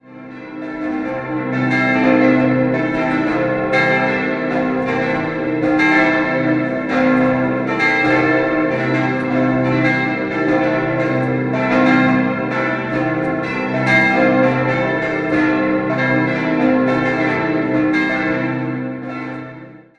5-stimmiges Geläute: c'-e'-g'-a'-h' Die große Glocke wurde im Jahr 1958 von Karl Czudnochowsky in Erding gegossen, alle anderen stammen von der Gießerei (Kuhn-)Wolfart in Lauingen aus den Jahren 1935 (Glocke 4) und 1950 (die übrigen).